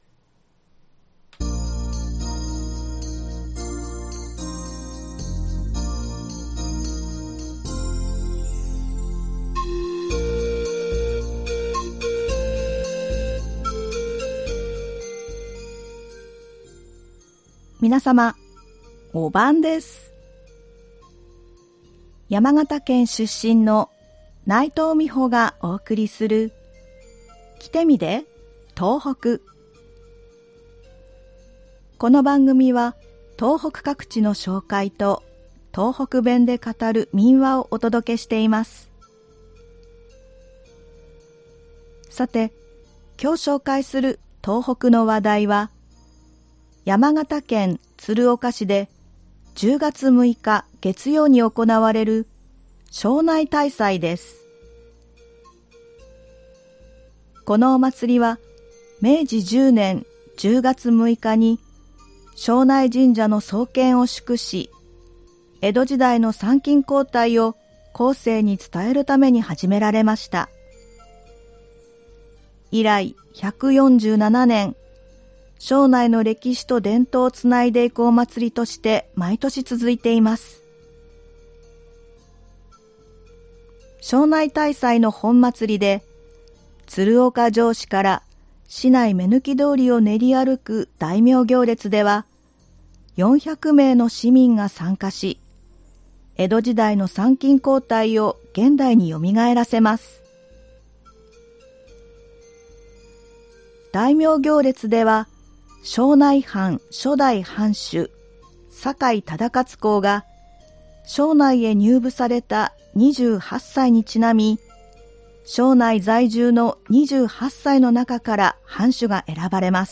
この番組は東北各地の紹介と、東北弁で語る民話をお届けしています。
今回は山形県で語られていた民話「行けざんざんの梨」です。